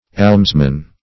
Almsman \Alms"man\, n.; fem. Almswoman.